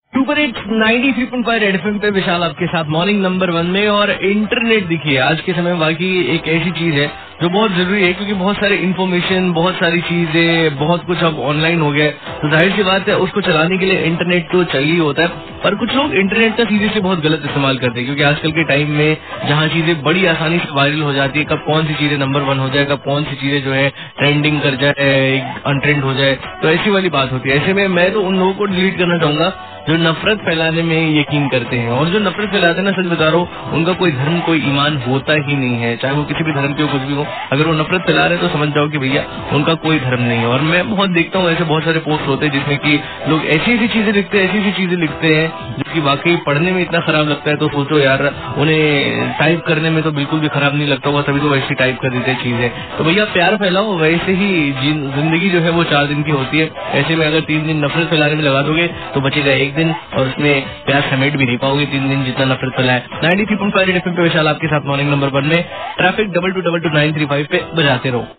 RJ TALKING ABOUT INTERNET